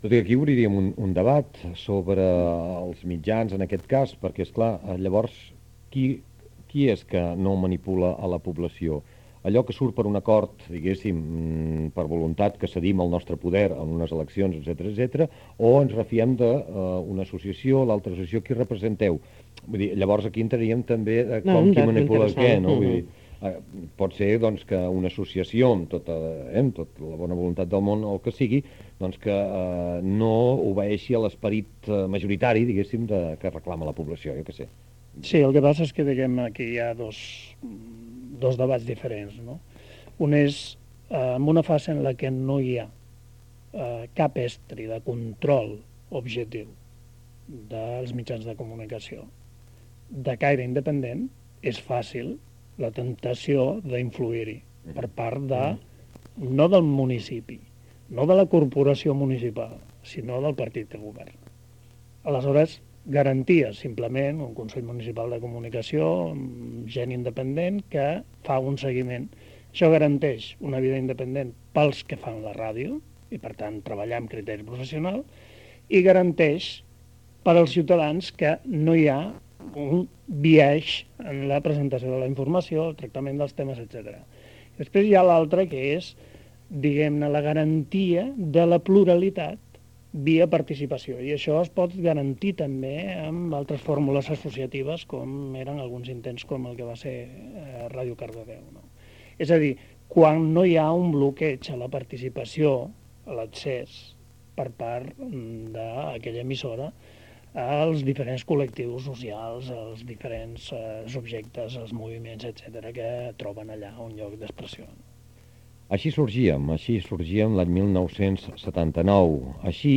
Divulgació
Fragment extret de l'arxiu sonor de COM Ràdio.